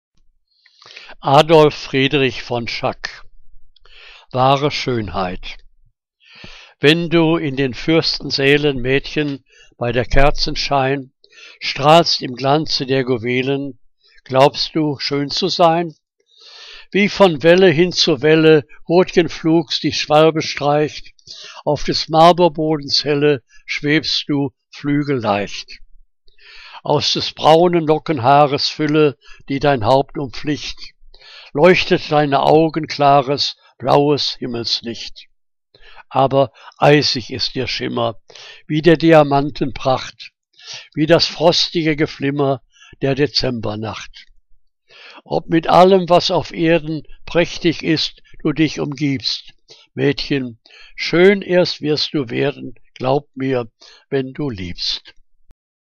Liebeslyrik deutscher Dichter und Dichterinnen - gesprochen (Adolf Friedrich von Schack)